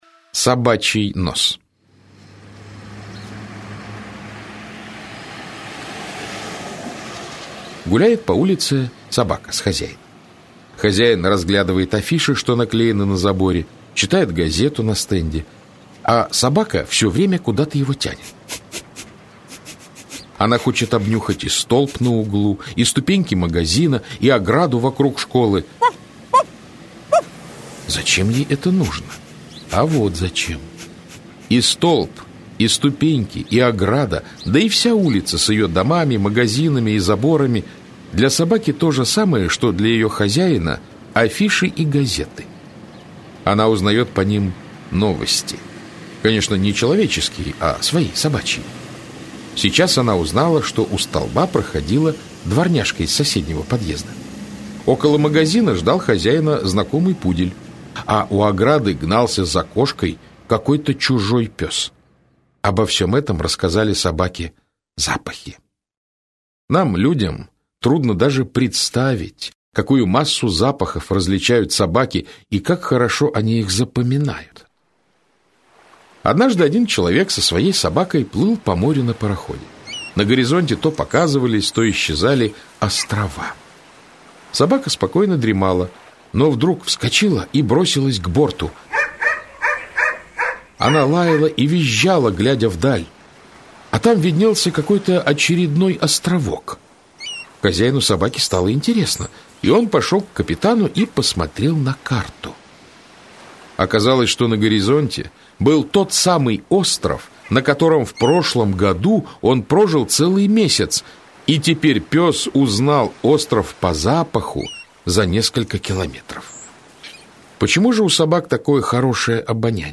Аудиокнига Для чего коту усы?
Автор Виталий Танасийчук Читает аудиокнигу Александр Клюквин.